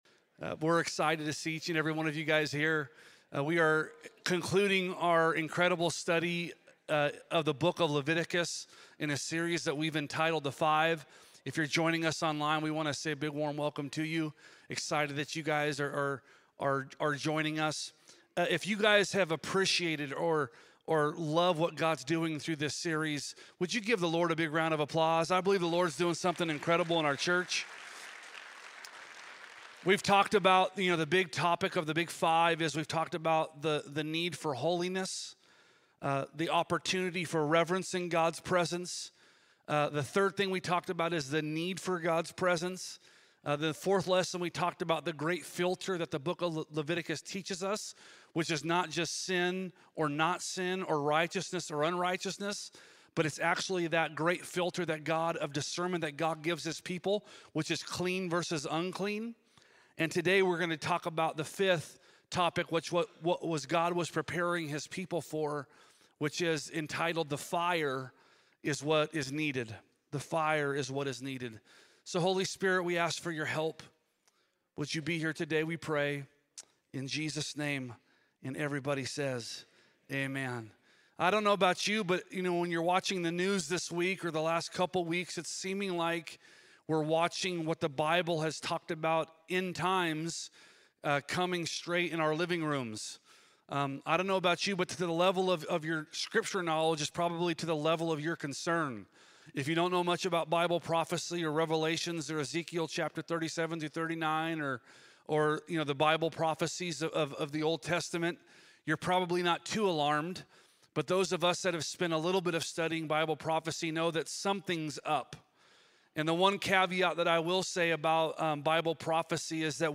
Each quarter we come together for a service